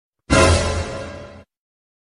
b shoking Meme Sound Effect